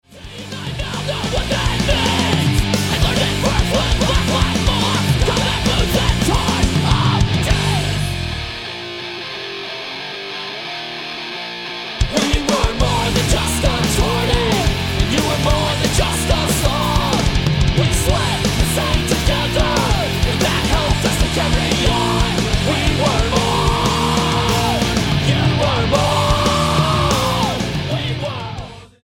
STYLE: Hymnody
The singing is good, but I have heard better.